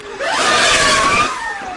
Audiobulb Tones " pad hi throb destroy
描述：audiobulb薄到扩张的悸动垫